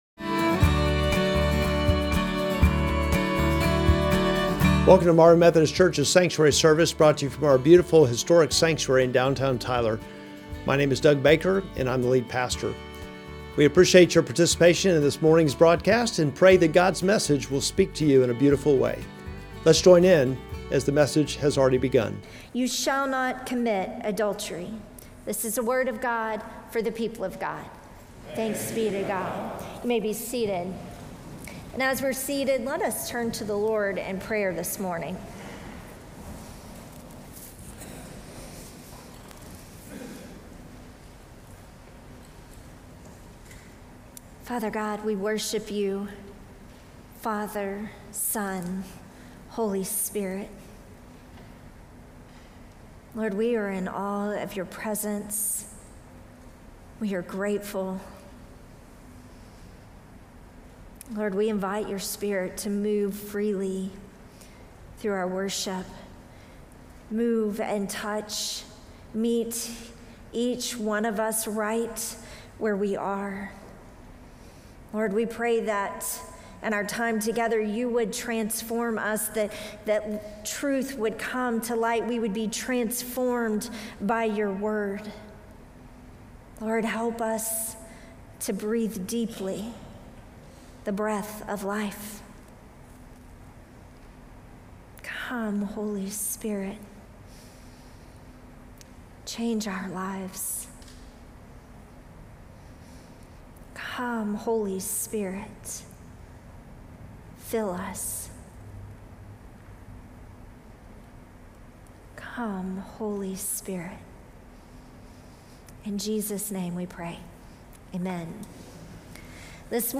Sermon text: Exodus 20:14